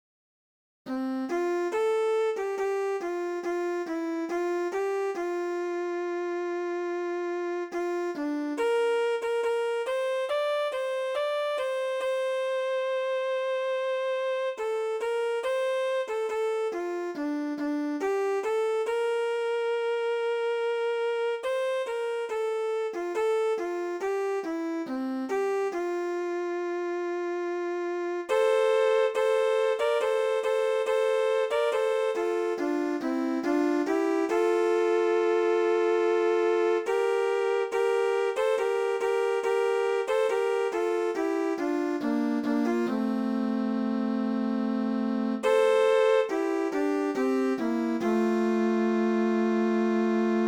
Thánh Ca Phụng Vụ